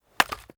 Chopping and Mining
chop 1.ogg